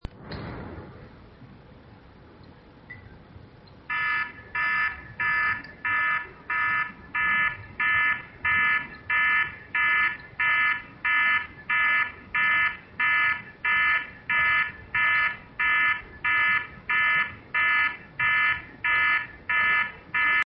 ALARMA
Descargar EFECTO DE SONIDO DE AMBIENTE ALARMA - Tono móvil
Alarma.mp3